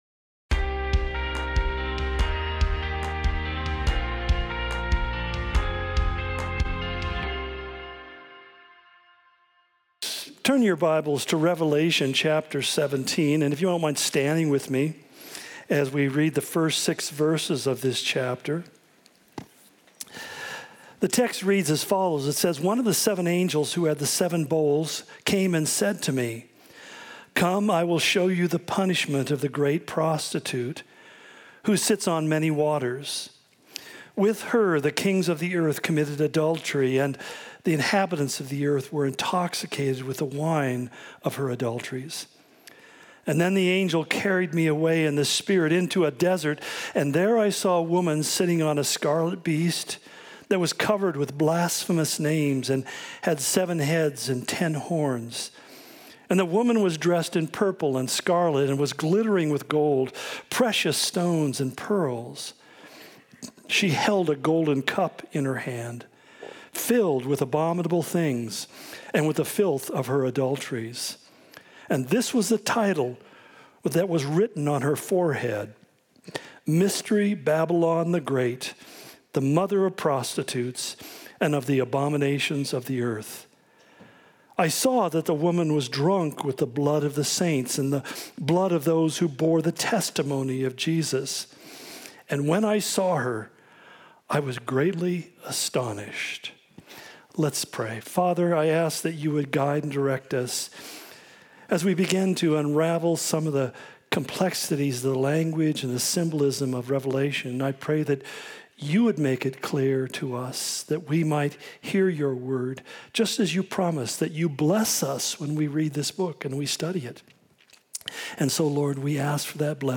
Similar to Calvary Spokane Sermon of the Week